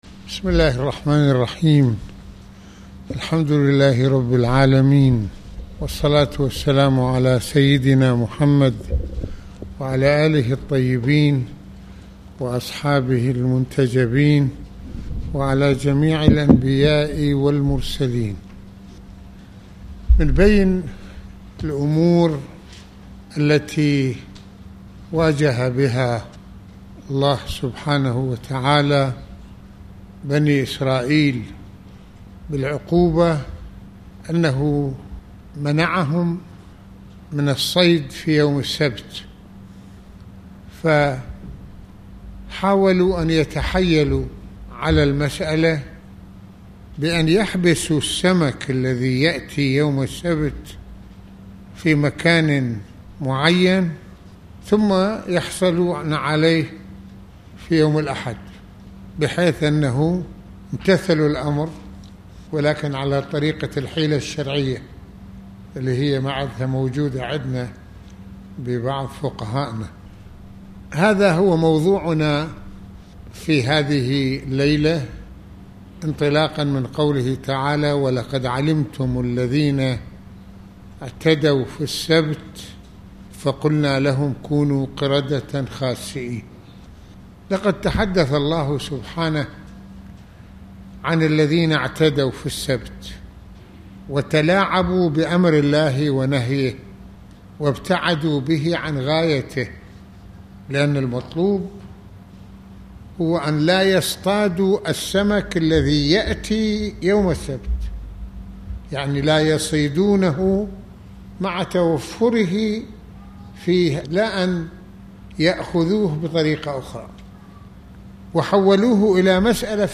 - المناسبة : خطبة الجمعة المكان : مسجد الإمامين الحسنين (ع) المدة : 27د | 24ث المواضيع : امتحان الله تعالى لبني اسرائيل في منعهم من الصيد في يوم السبت ؟